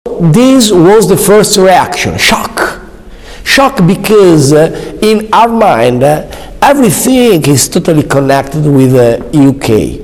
Play, download and share first reaction shock original sound button!!!!
first-reaction-shock.mp3